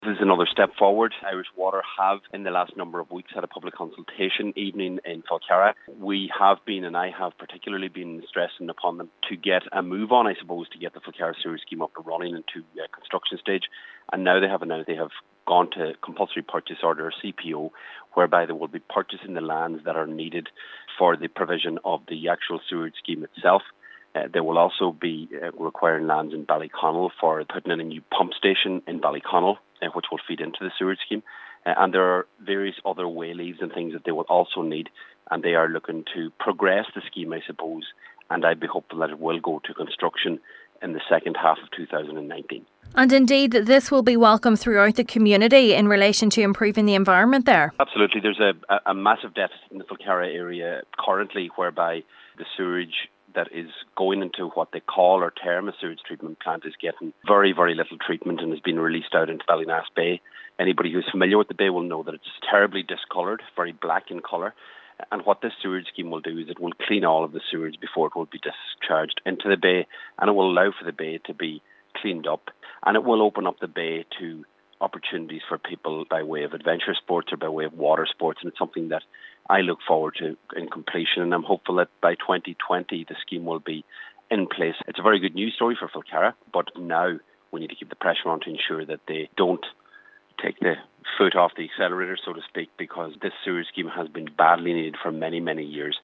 Cathaoirleach of Donegal County Council Councillor Seamus O’Domhnaill says the scheme once up and running will greatly enhance the local area: